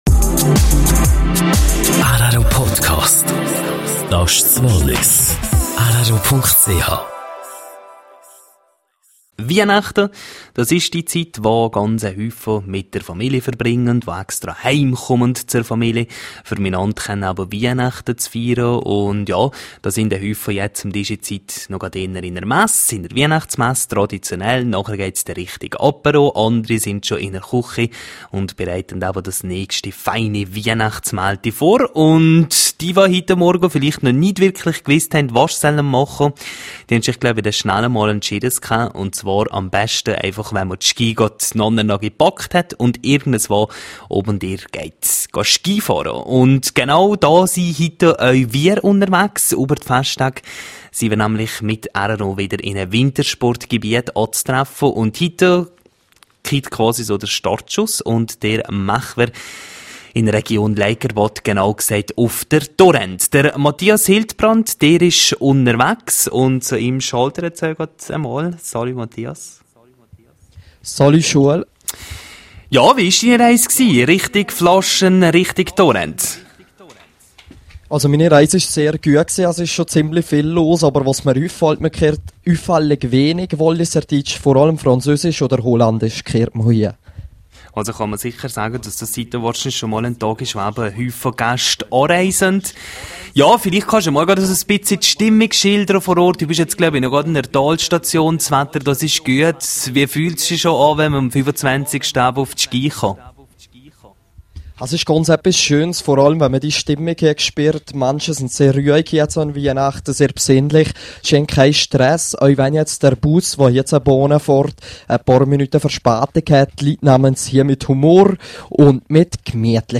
rro unterwegs auf Torrent: Einschaltung